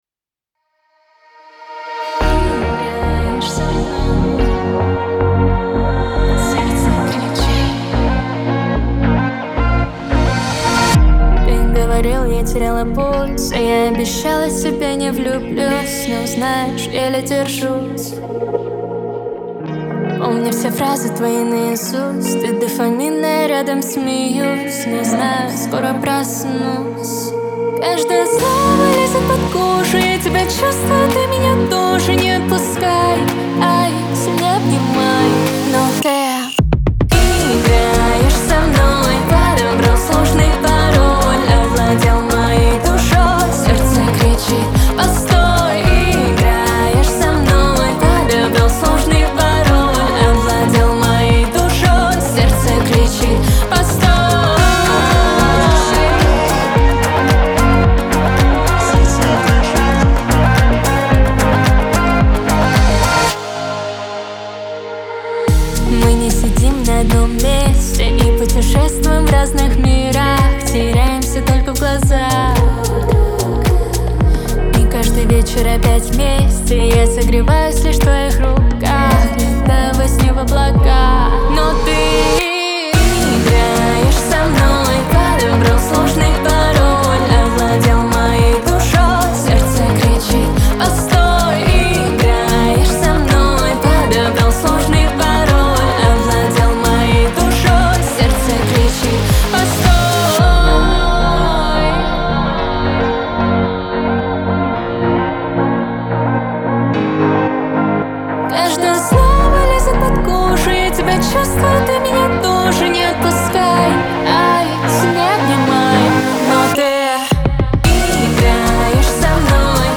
pop , Лирика